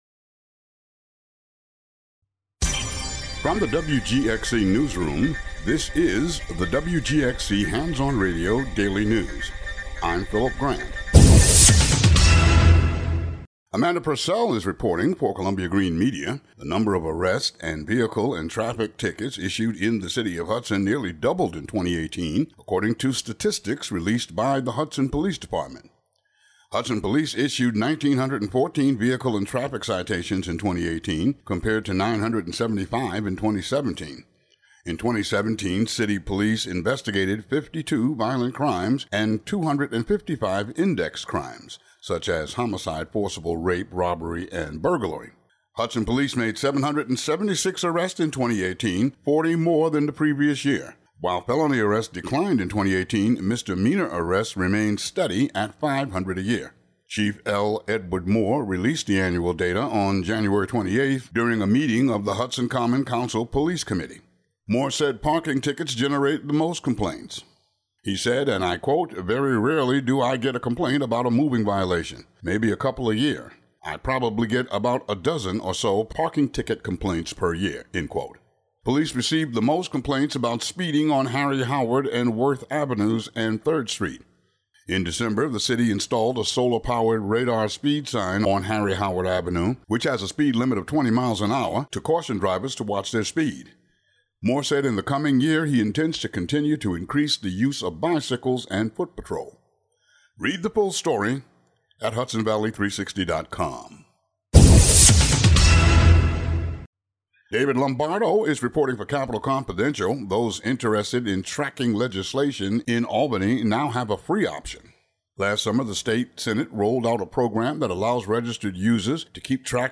Today's daily news.